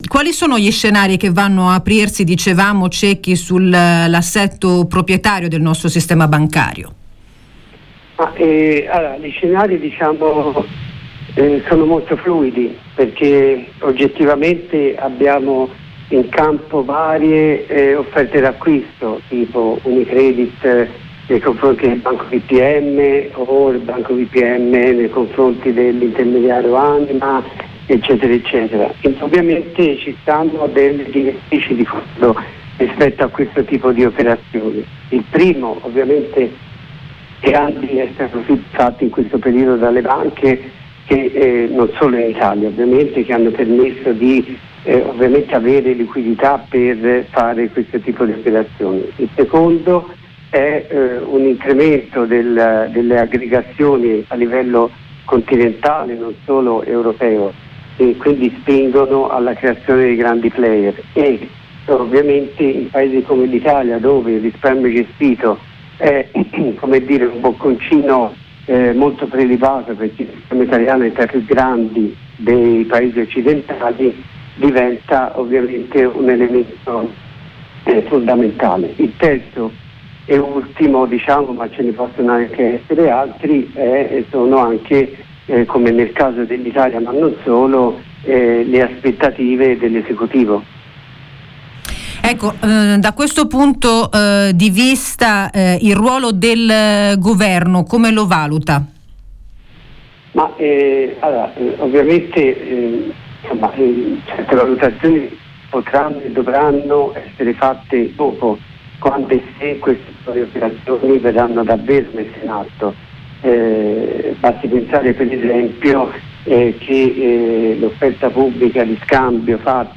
L’offerta pubblica di scambio di Monte dei Paschi, doppio esame dei mercati e del vertice di mediobanca. Gli assetti proprietari del sistema bancario e il ruolo del Governo. Controradio intervista